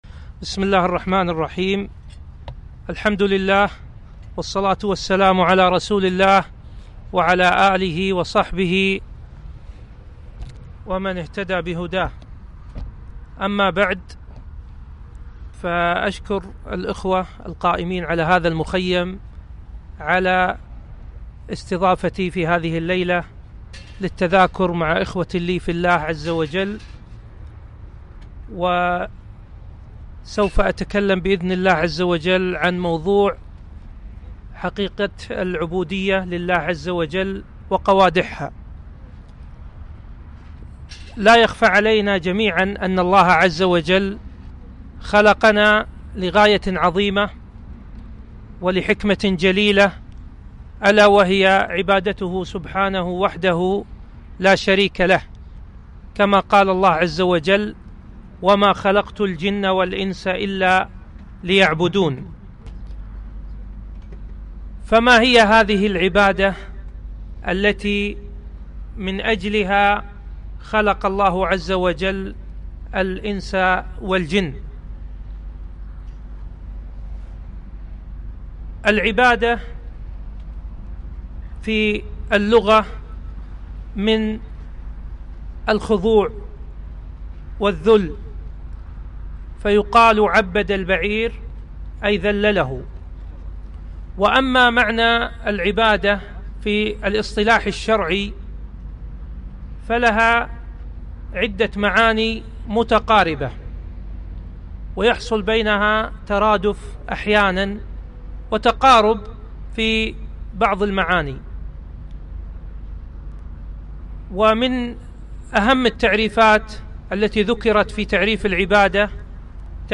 محاضرة - حقيقة العبودية